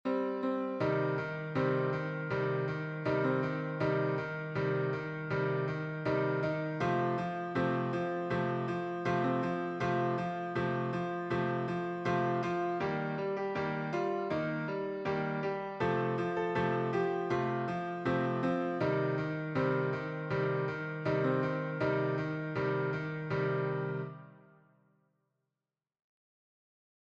(Recording) Unitarian Universalist hymn set to my own music